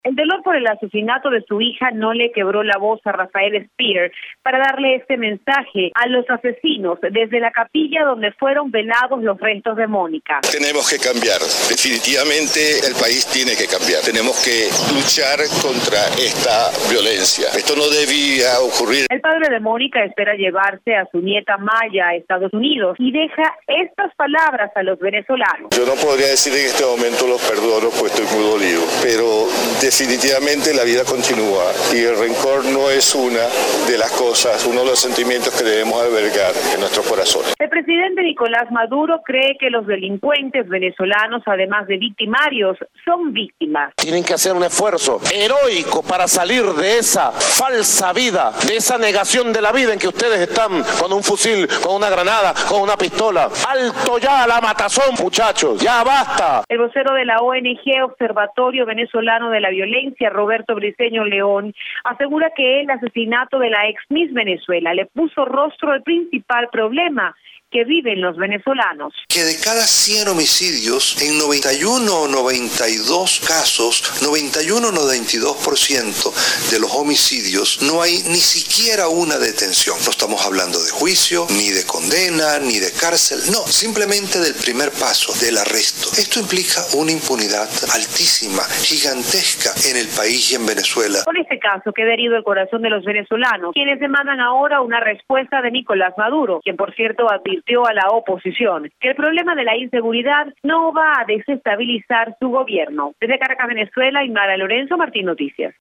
Desde Caracas